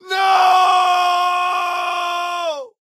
scream.ogg